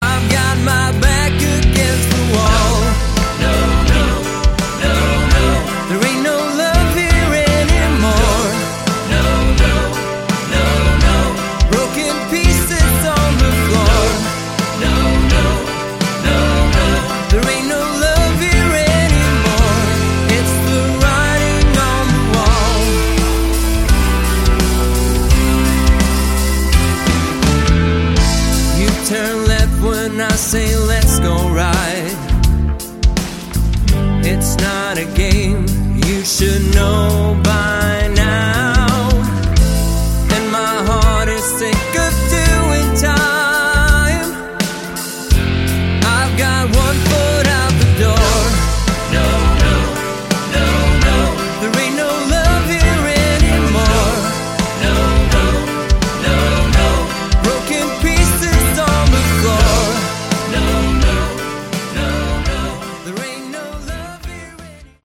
Category: AOR / Melodic Rock
Vocals, Guitars
Piano, Keyboards
Bass
Drums